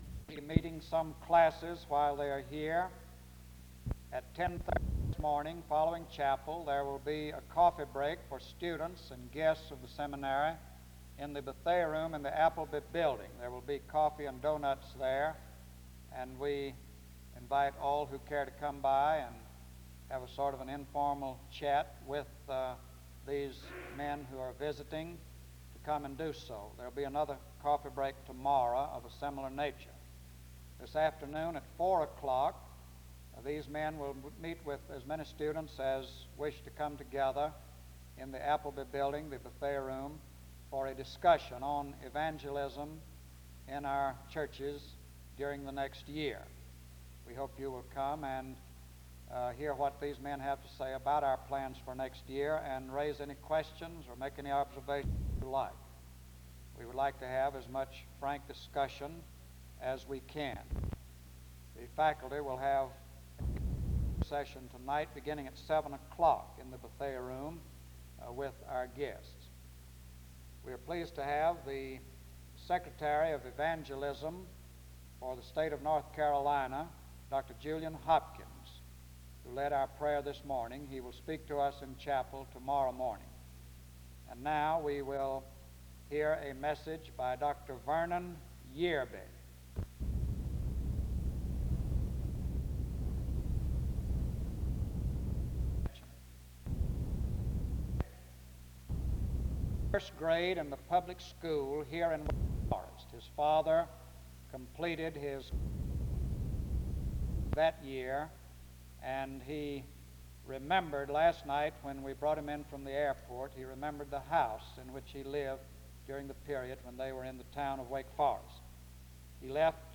The service begins with announcements and an introduction to the speaker from 0:00-2:50.